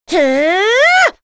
Chrisha-Vox_Casting1.wav